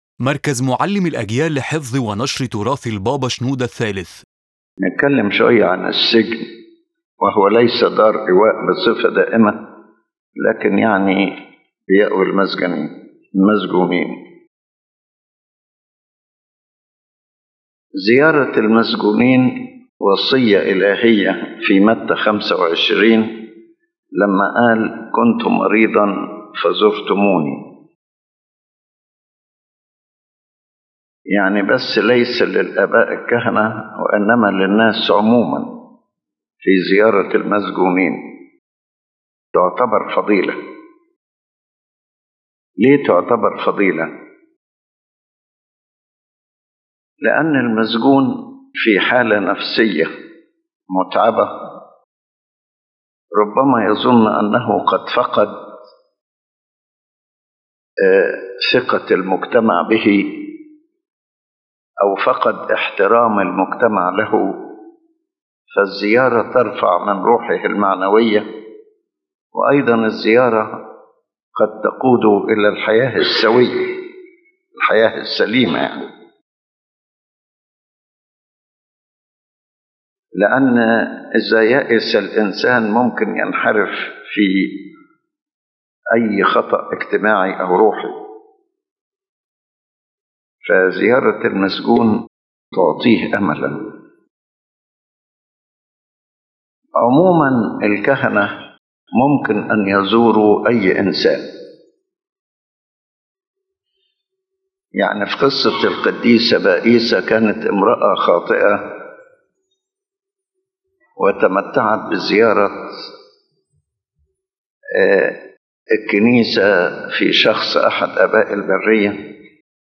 General core: The lecture speaks about the importance of caring for prisoners from a spiritual and social perspective, and shows how visiting prisoners and providing help raises their morale and gives them hope and protects them from deviation. The lecture also emphasizes practical aspects: legal defense, material support, psychological support, and their rehabilitation after release.